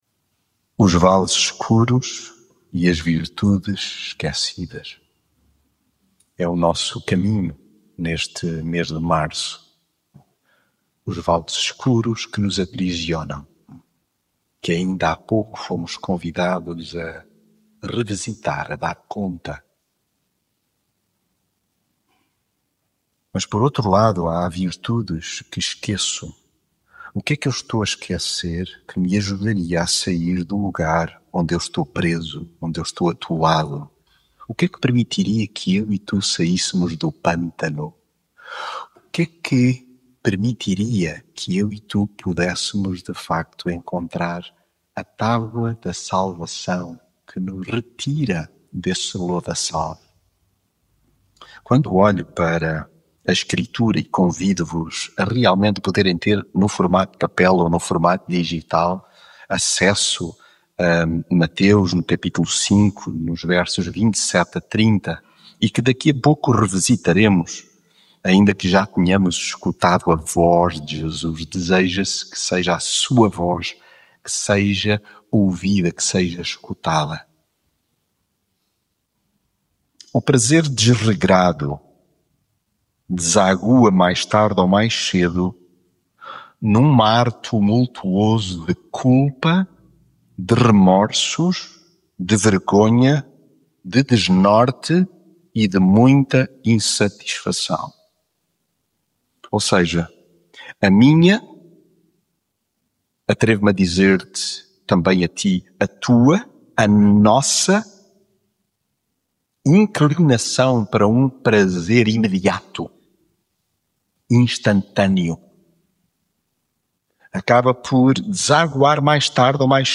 mensagem bíblica O prazer desregrado desagua, mais tarde ou mais cedo, num mar tumultuoso de culpa, remorsos, vergonha, desnorte e muita muita insatisfação.